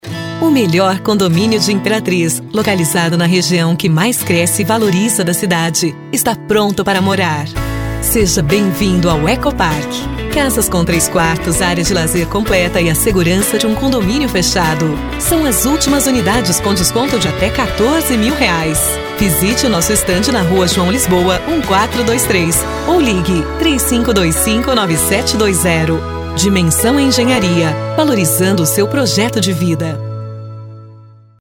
Female
Teenager (13-17), Adult (30-50)
Accents: I have a warm, clear tone but extremely versatile and I can do a great many voices.
Radio Commercials
All our voice actors have professional broadcast quality recording studios.